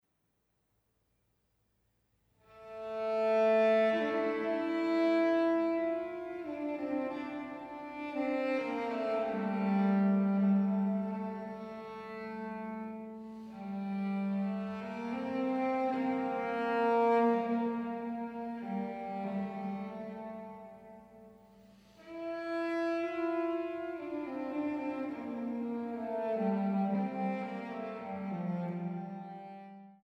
Violoncello und Arrangements